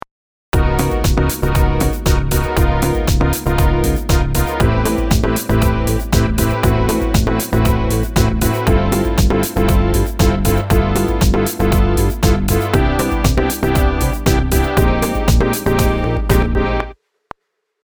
そこでエフェクト成分を逆側に同じ程度振りつつ左右のバランスをとってみましょう。
こんな感じにしてみると、左側のシンセブラスは前に、エフェクト音の右側はちょっと後ろに感じるようになります。